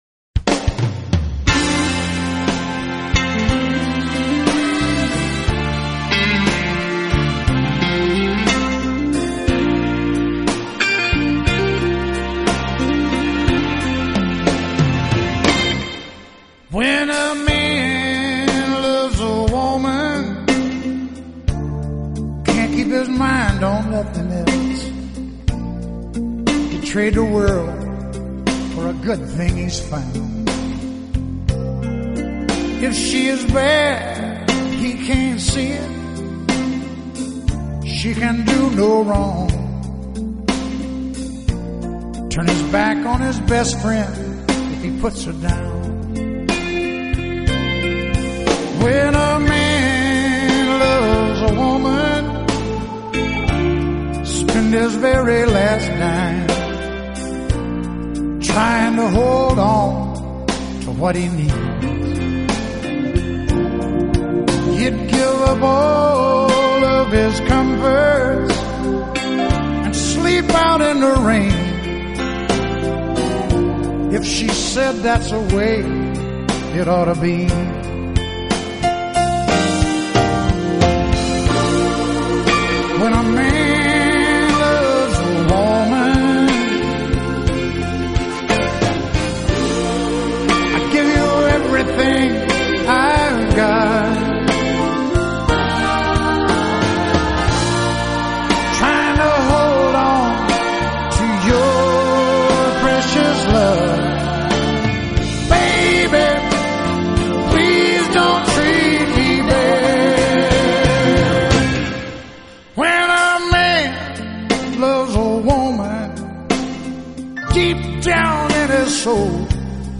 声线浪漫动人，加上发烧录音，让人一听难忘，百听不厌！